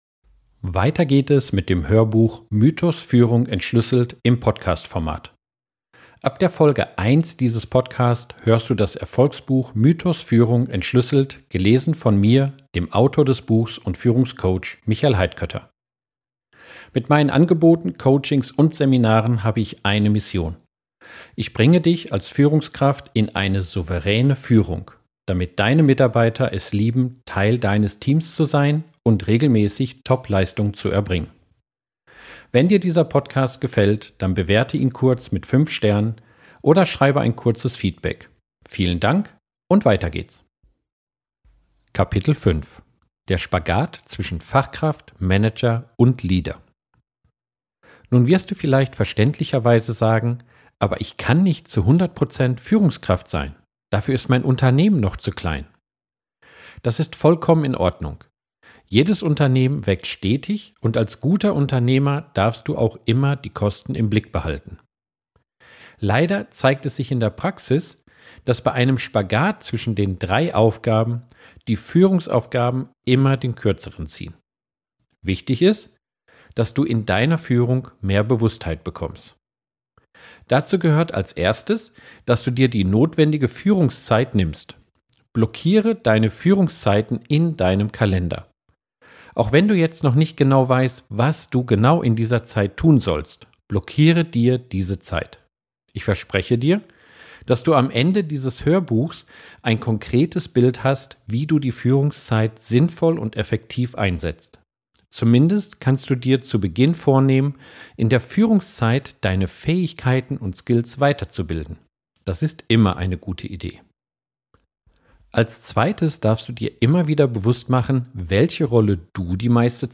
Du erhältst das vollständige Hörbuch gratis und ganz bequem im